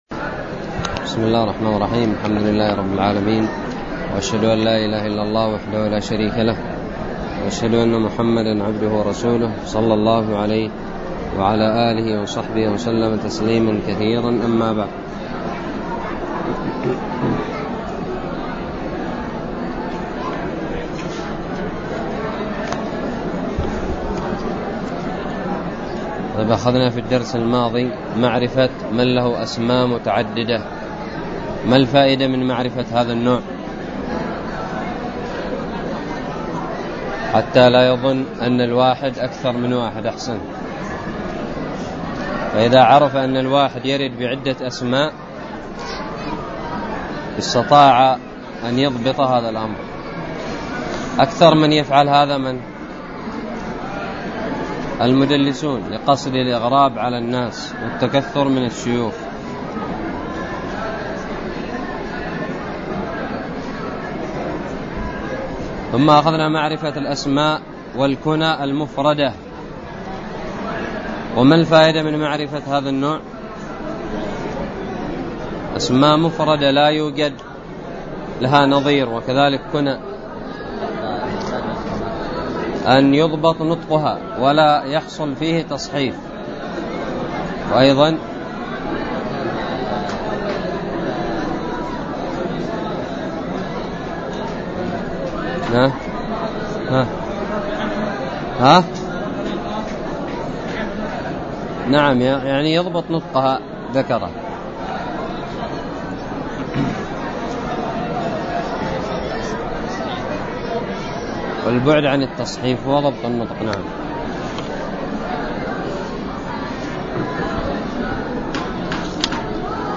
ألقيت بدار الحديث السلفية للعلوم الشرعية بالضالع